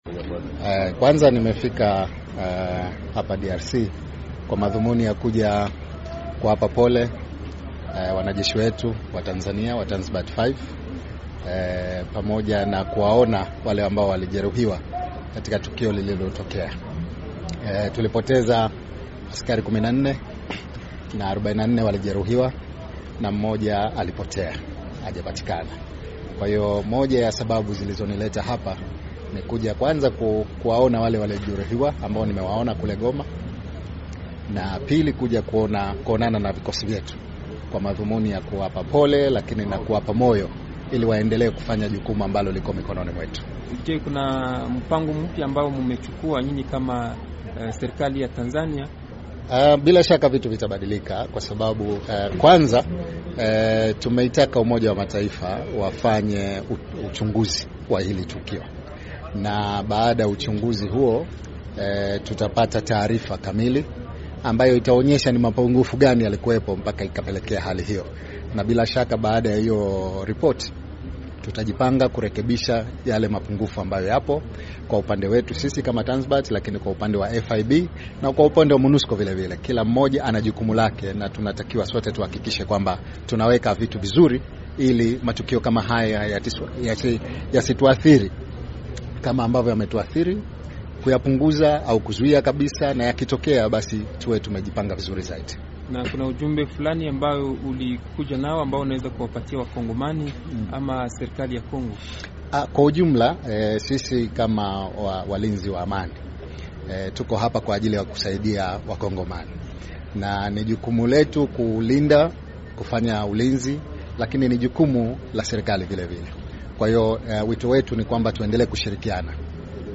Waziri wa ulinzi wa Tanzania Mwinyi azungumza akiwa Beni
Akizungumza na waandishi wa habari kwenye uwanja wa ndege wa Beni, Waziri Mwinyi alisema amefika kuwapa pole wanajeshi wa Tanzania na kuwatembelea walojeruhiwa pamoja na kuwapa moyo kuendelea na jukumu lao la kurudisha amani katika eneo la mashariki ya Kongo.